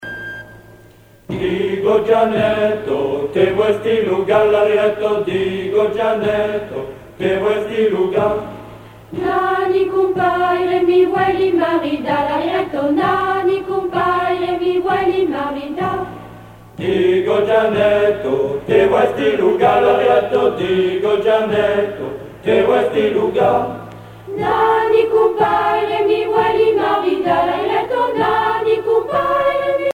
Région ou province Provence Fonction d'après l'analyste danse : rigaudon
Genre strophique
Catégorie Pièce musicale éditée